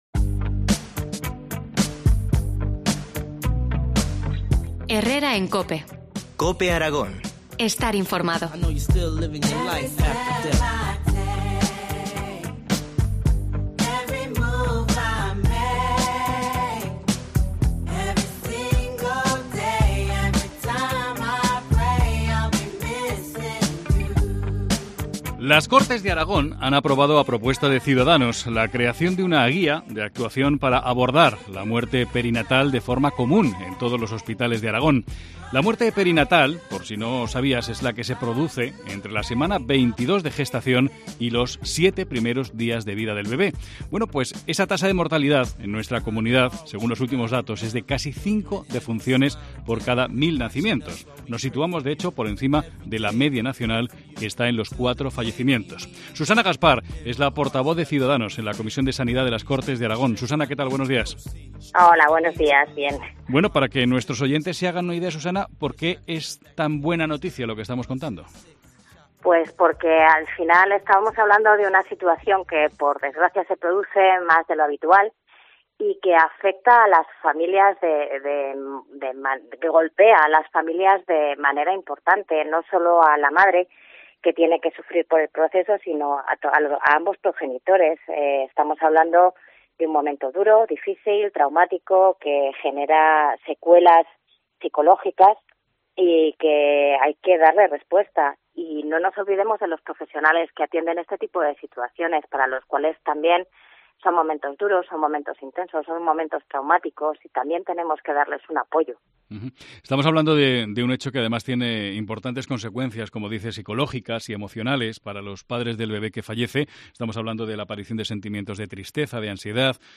Entrevista a la portavoz de Sanidad de Cs, Susana Gaspar, sobre la muerte perinatal.